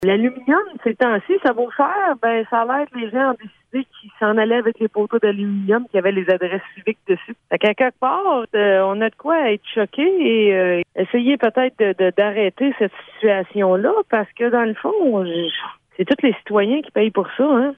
La mairesse Anne Potvin n’en revient pas de la situation et est très mécontente.